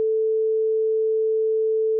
Sine Wave 2 sec.wav